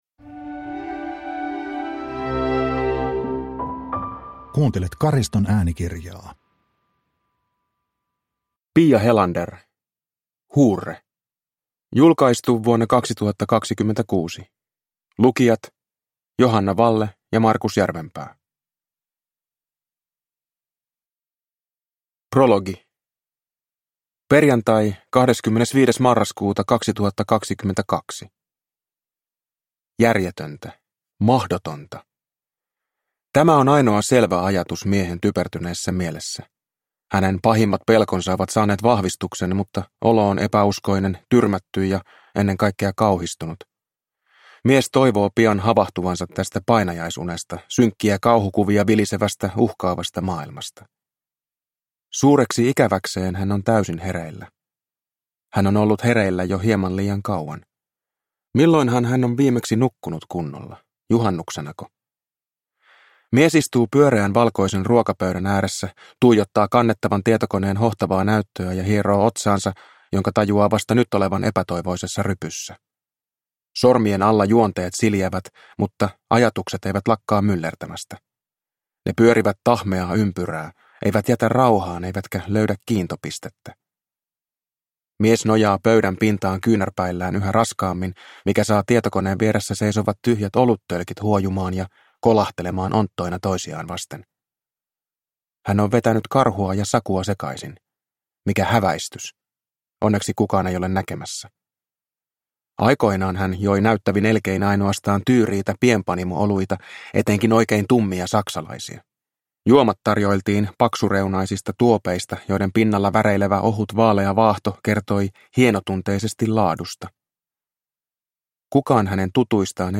Huurre (ljudbok) av Piia Helander